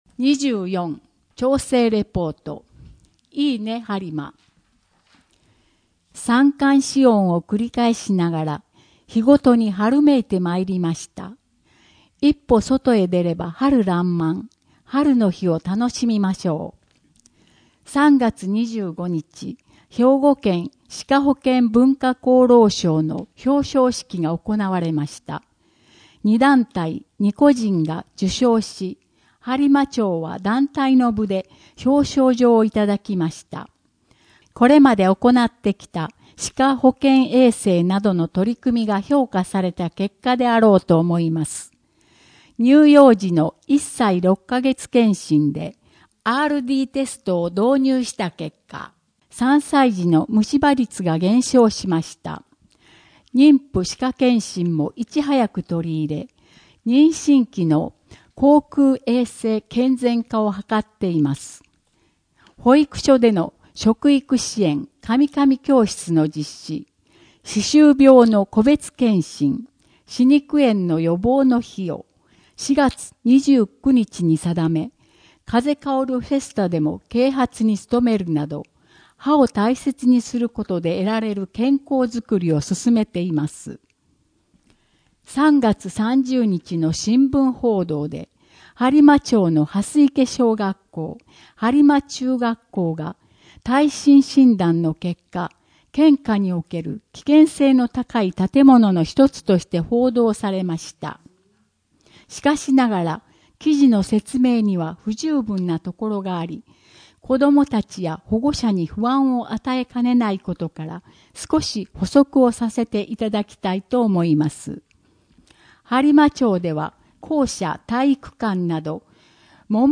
声の「広報はりま」はボランティアグループ「のぎく」のご協力により作成されています。